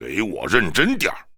文件 文件历史 文件用途 全域文件用途 Enjo_skill_01_2.ogg （Ogg Vorbis声音文件，长度1.4秒，120 kbps，文件大小：20 KB） 源地址:地下城与勇士游戏语音 文件历史 点击某个日期/时间查看对应时刻的文件。